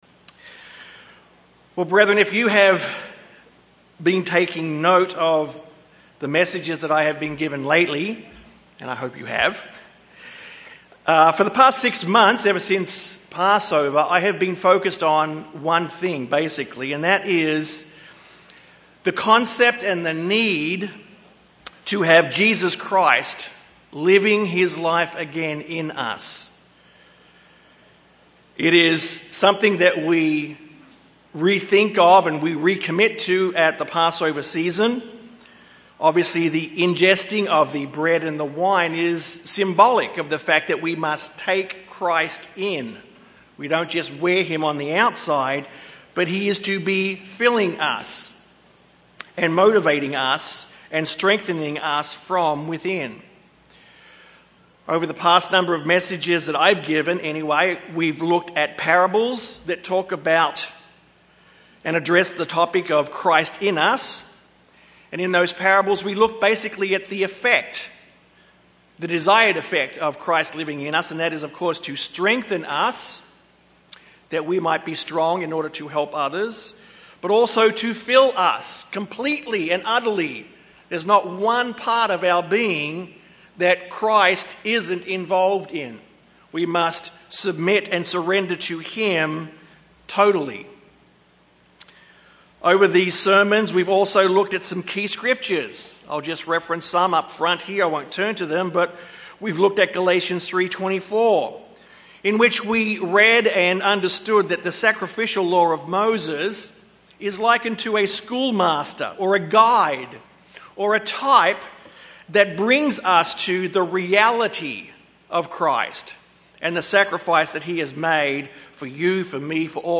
While it is true that Christ alone is our means to Salvation, does this mean that Salvation has no conditions? This sermon speaks to the fact that as Disciples of Christ, we are to be Walking in Righteousness. It addresses 3 fundamental areas in which we are to walk as Children of the Kingdom.